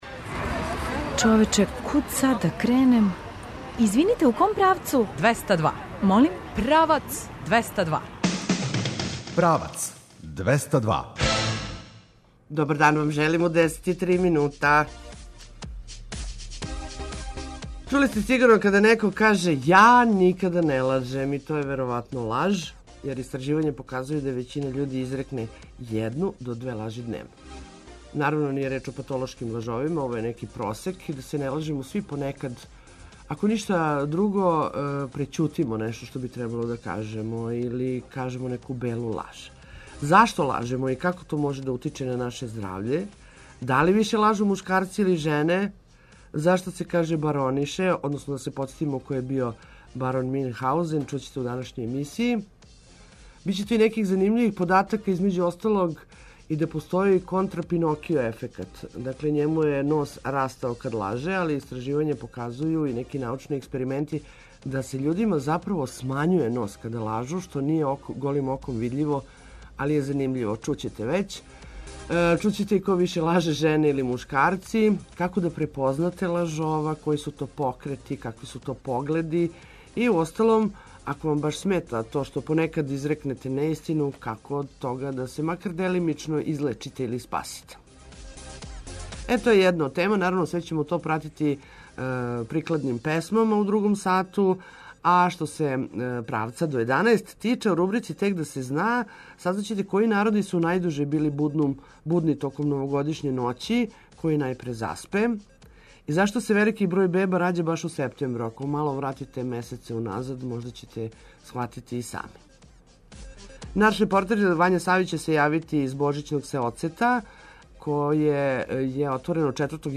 Од нашег репортера ћете чути шта вас очекује у „Божићном сеоцету” испред Храма Светог Саве.